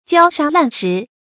焦沙烂石 jiāo shā shí làn
焦沙烂石发音